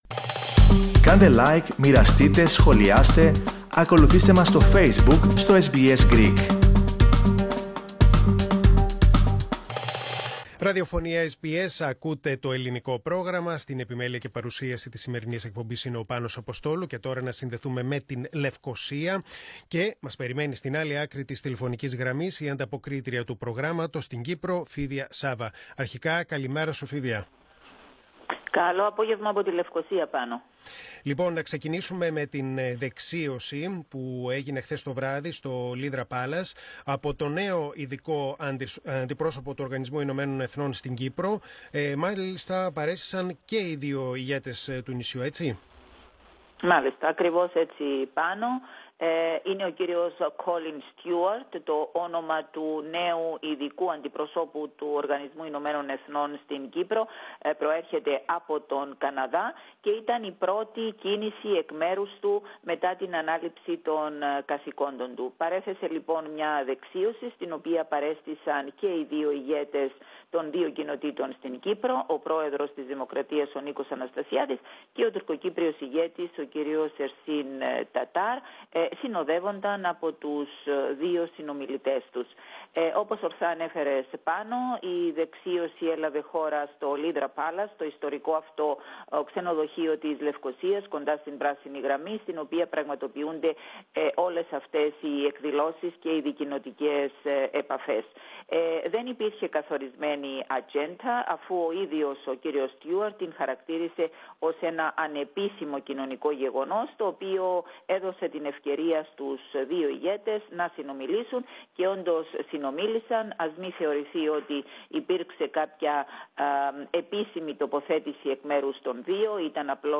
antapokrisi_kyprou.mp3